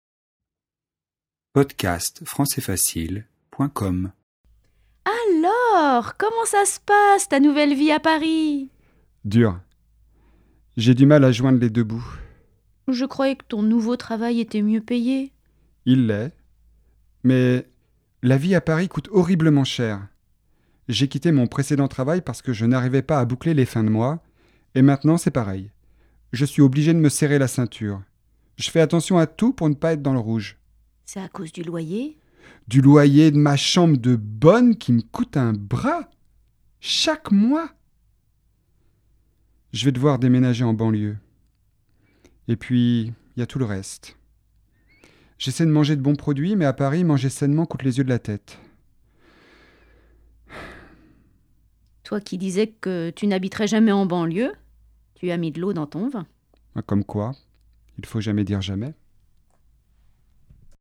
• Registre : Informel
🔷 DIALOGUE